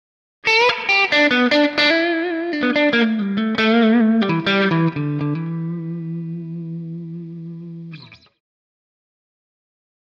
Electric Guitar
Blues Guitar - Short Solo 4